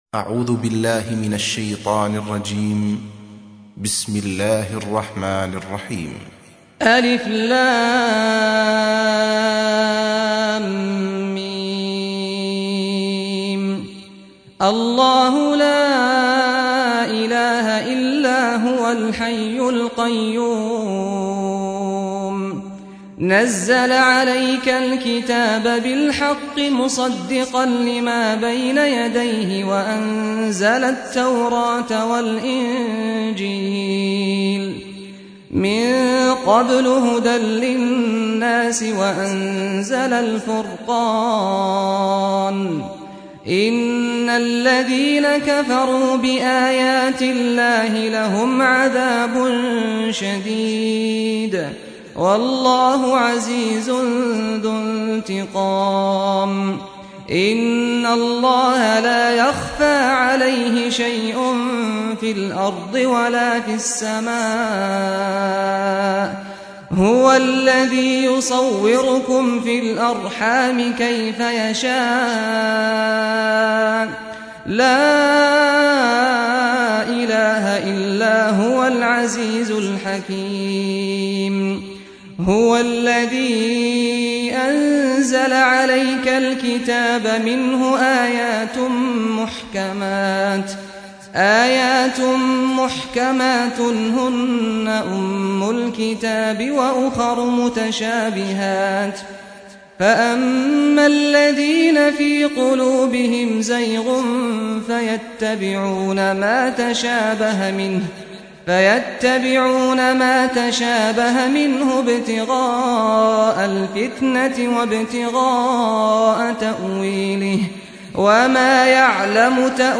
سُورَةُ ٓآلِ عِمۡرَانَ بصوت الشيخ سعد الغامدي